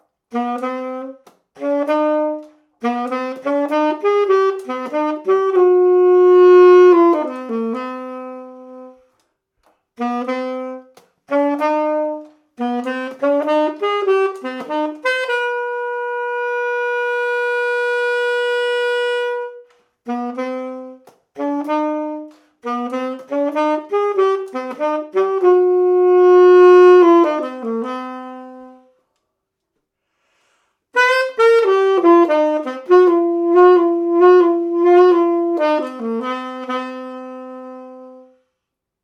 Bec de saxophone ténor
On entend également très bien le bruit des clés ;-).
• Sax : Yamaha YTS-23
• Anche : Vandoren Classique 3 1/2
• Enregistreur : Tascam DR-100mkII
• Export : Audacity "BIO / sans traitement"